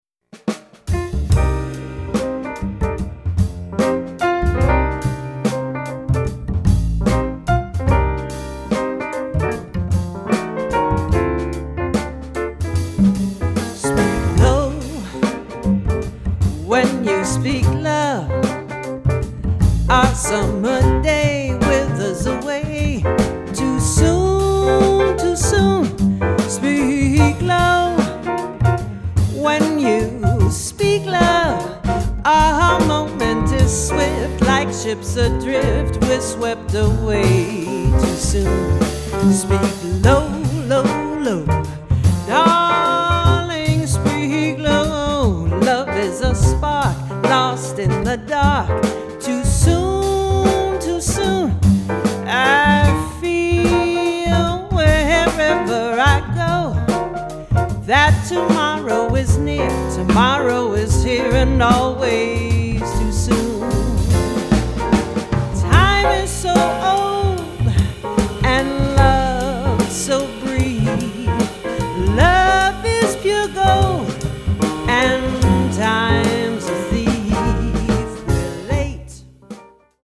tenor sax and vocals
piano
bass
-drums